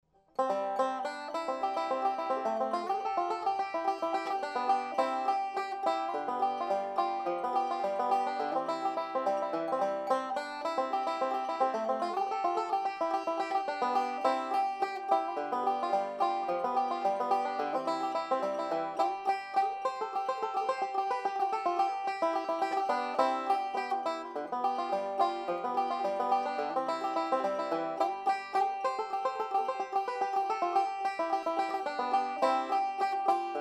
These rings produce a great tone with no loss of volume.
2000 bellbird banjo with carbon fibre ring . Remo head , fults tailpiece
bellbird-carbon-fibre-2.mp3